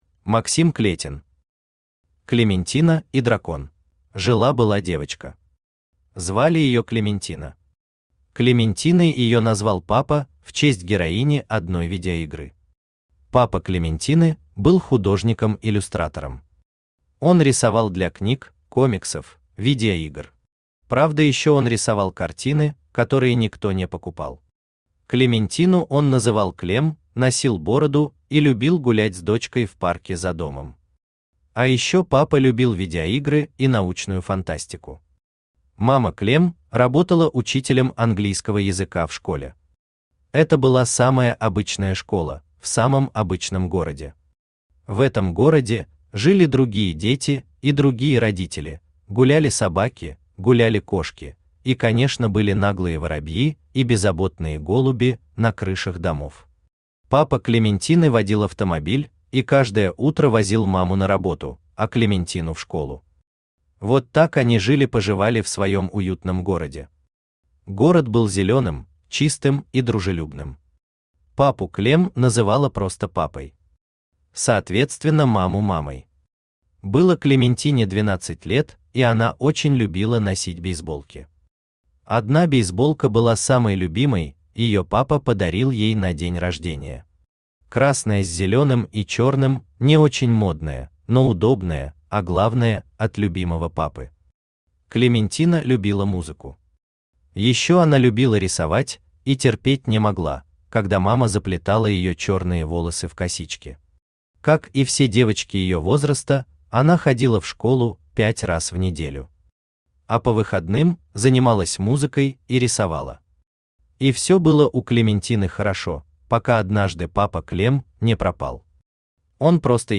Аудиокнига Клементина и Дракон | Библиотека аудиокниг
Aудиокнига Клементина и Дракон Автор Максим Клетин Читает аудиокнигу Авточтец ЛитРес.